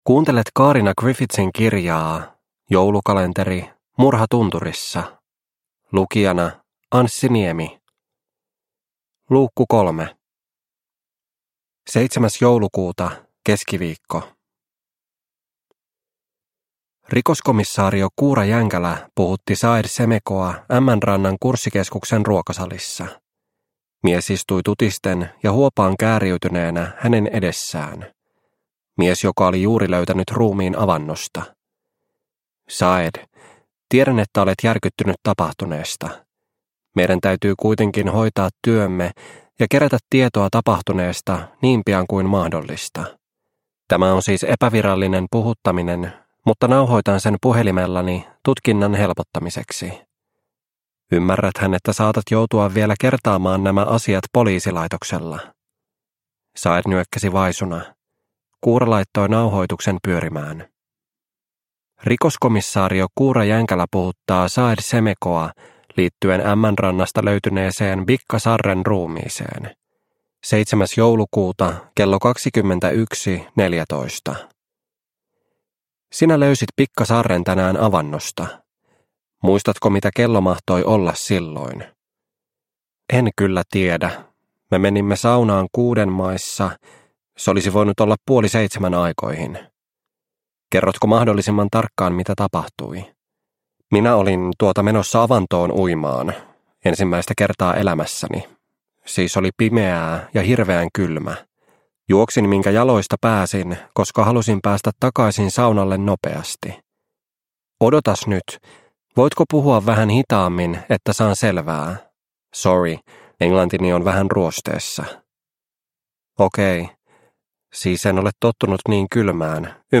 Murha tunturissa - Osa 3 – Ljudbok – Laddas ner